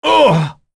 Roi-Vox_Damage_04.wav